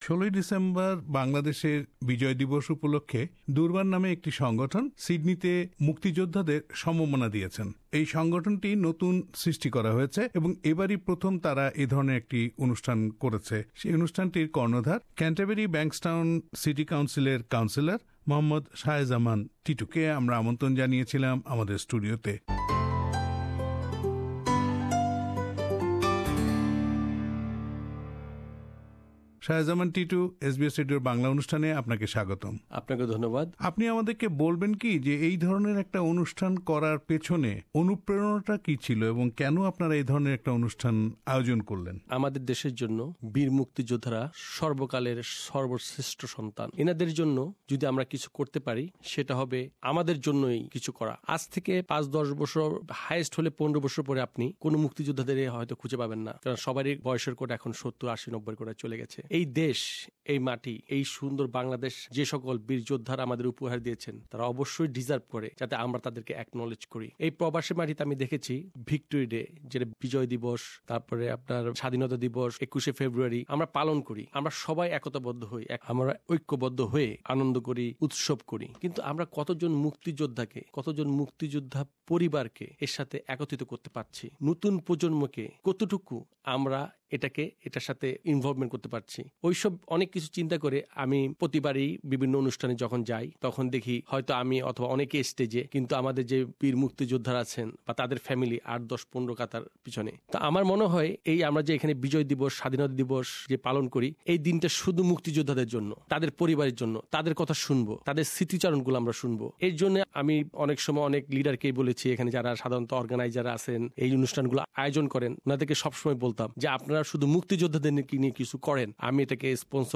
Durbar a newly formed organisation honoured the freedom fighters of Bangladesh: Interview with Councillor Mohammad Shahe Zaman Titu
Interview with Councillor Zaman Titu